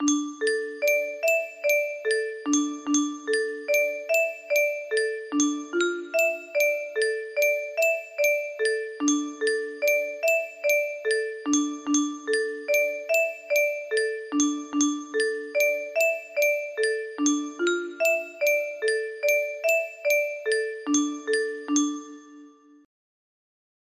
Hårgalåten inspired music box melody